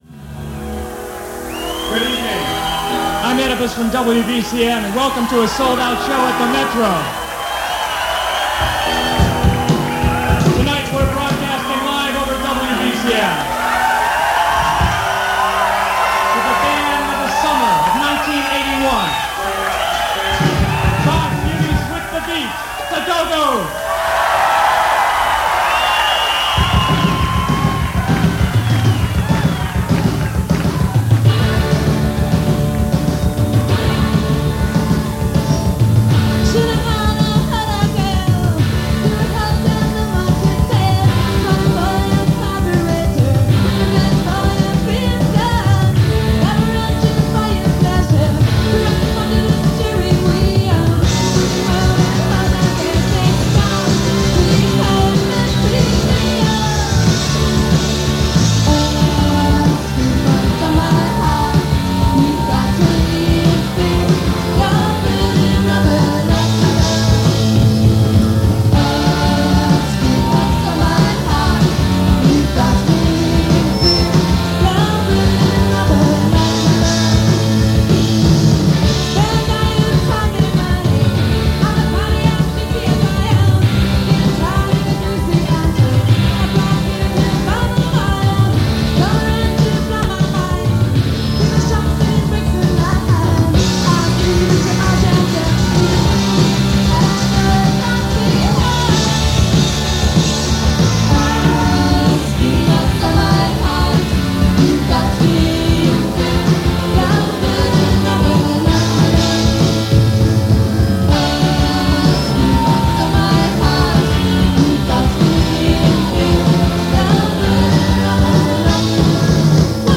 lead guitar and keyboards
lead vocals
drums
bass guitar
rhythm guitar
One of the cornerstone bands of America’s New Wave movement.